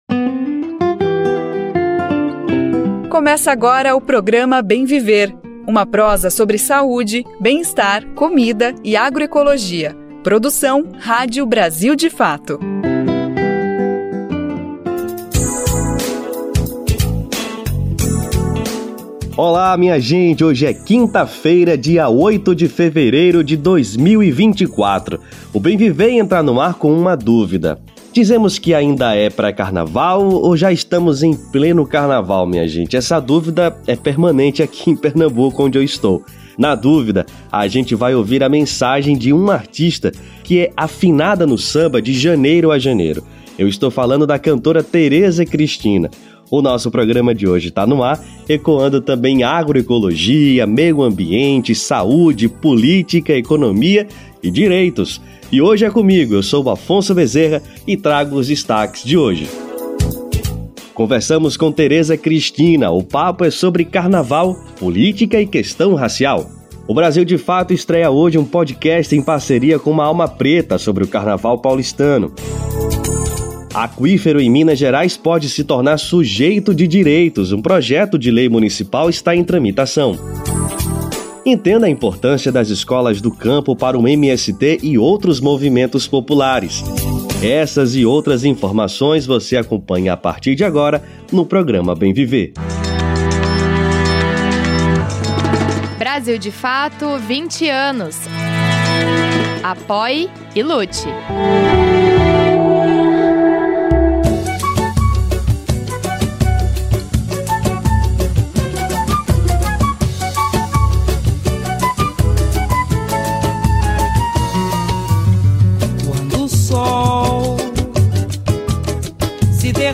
‘Não consigo desassociar o carnaval da história do povo preto’, diz cantora Teresa Cristina